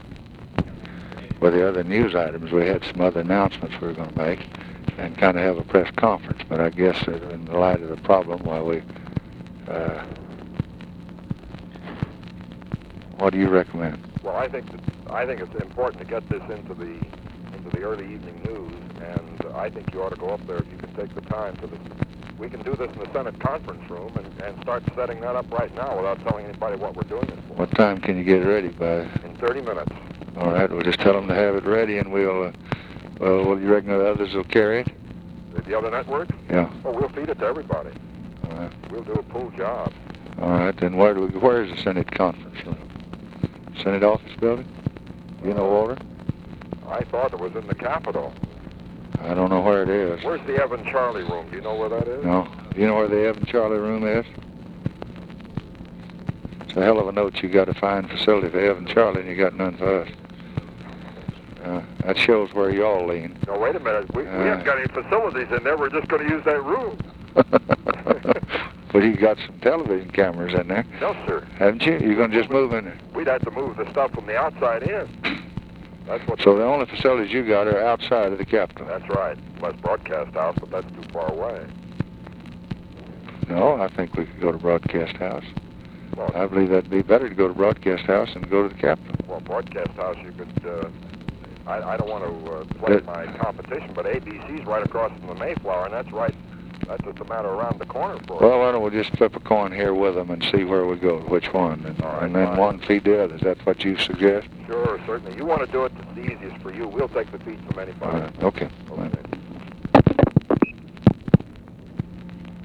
Conversation with FRANK STANTON, April 22, 1964
Secret White House Tapes